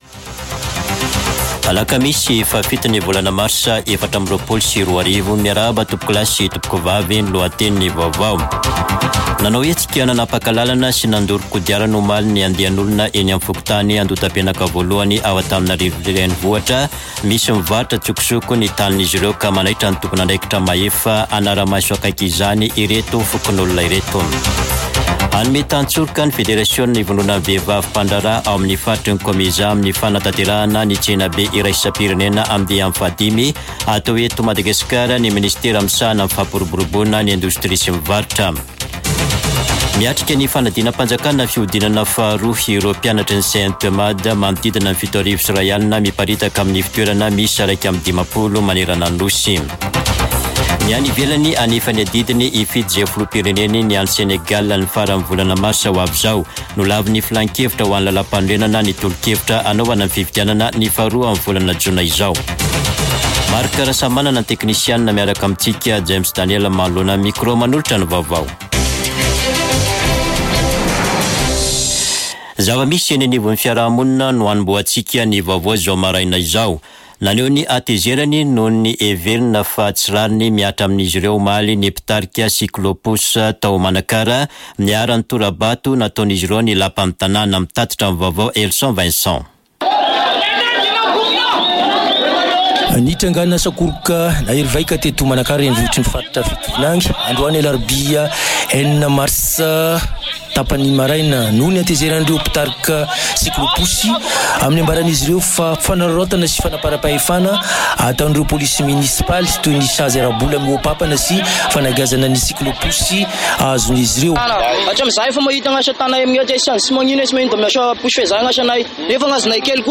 [Vaovao maraina] Alakamisy 7 marsa 2024